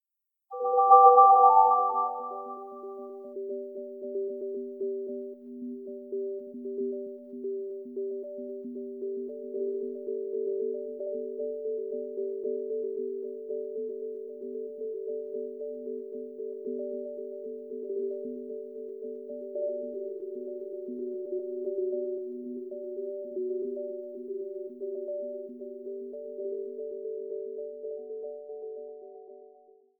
Main menu theme
Fair use music sample